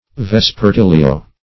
Vespertilio \Ves`per*til"i*o\